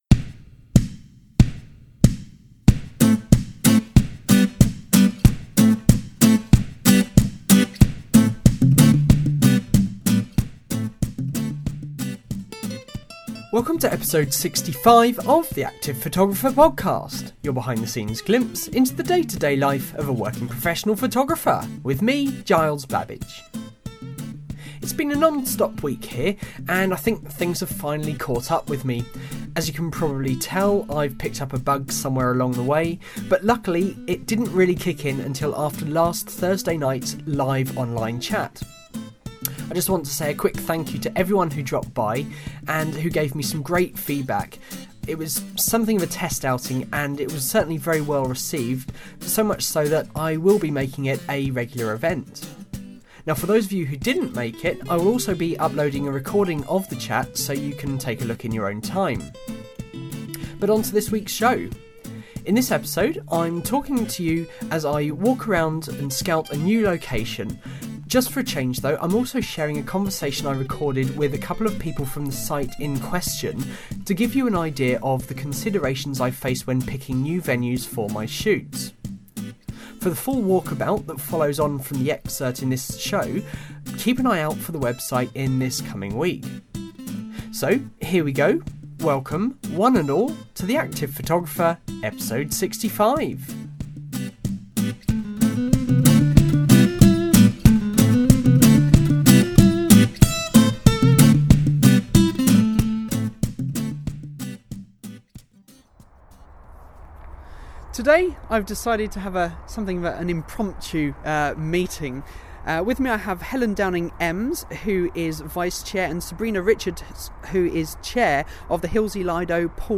This week: I’m taking you out and about as I scout a new location.
I’m also sharing a conversation I recorded with a couple of people from the site in question, to give you an idea of the considerations I face when picking new venues for my shoots.